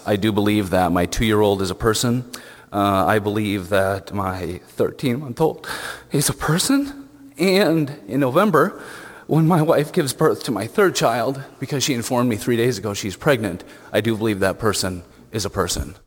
Representative Zach Dieken of Granville says fetus is a Latin word and he prefers that English be used in Iowa law.